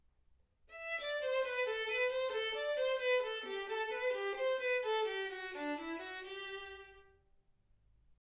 Hegedű etűdök
Classical music